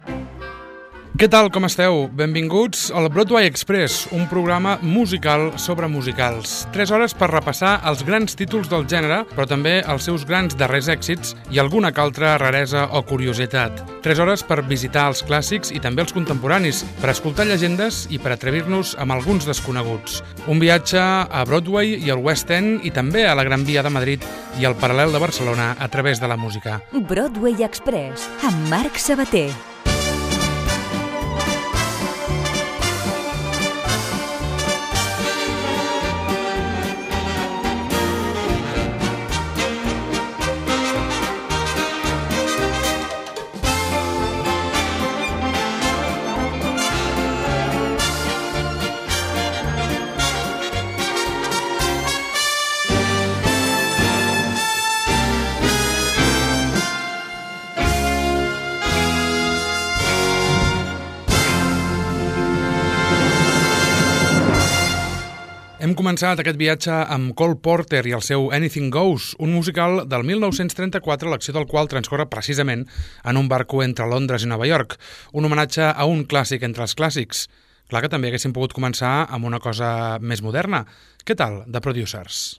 Presentació, careta i inici del programa sobre els grans musicals de Broadway, el West End, la Gran Vía madrilenya i el Paral·el barceloní.
Musical